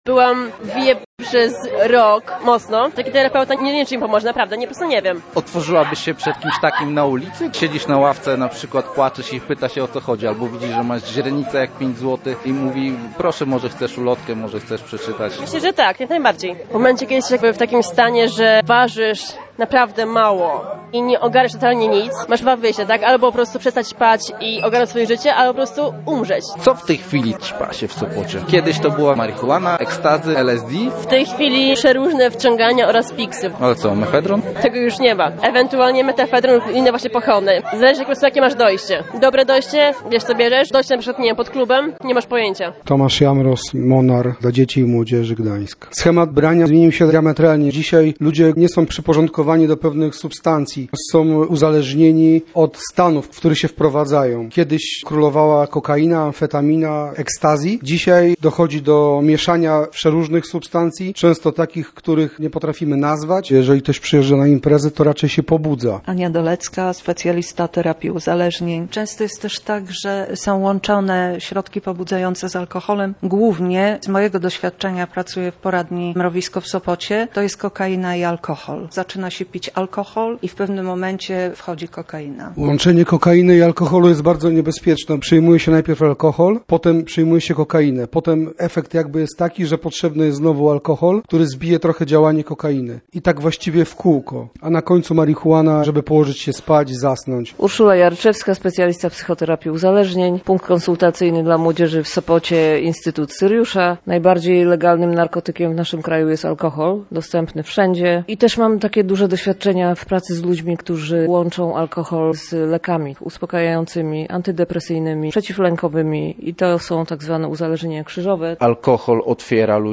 Sopot, północ. Na Monciaku gwar.